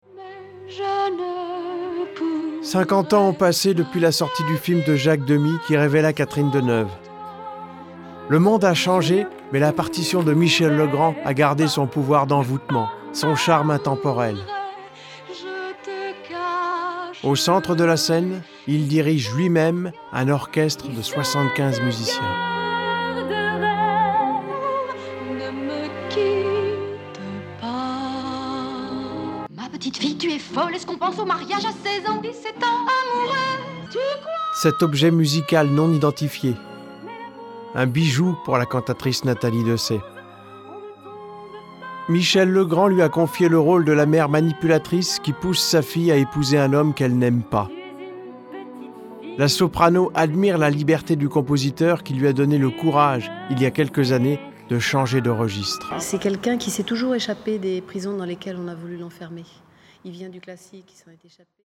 narration 2